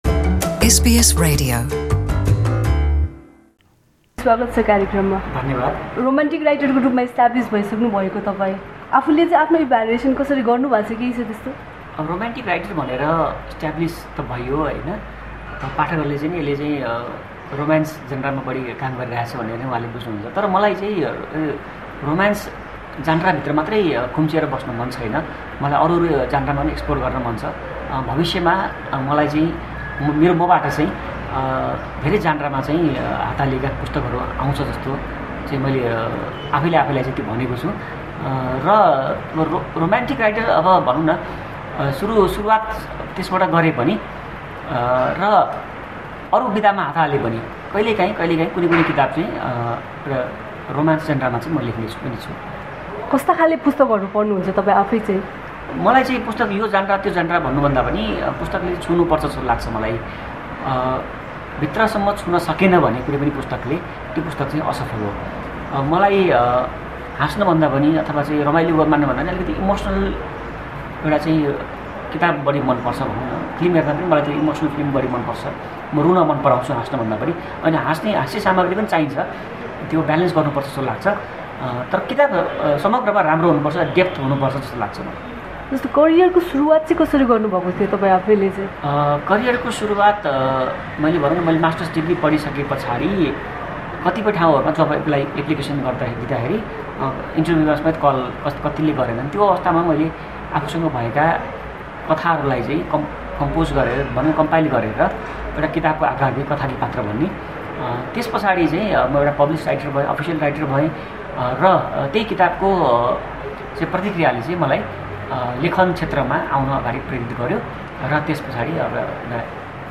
Nepali author Subin Bhattarai speaking to SBS Nepali.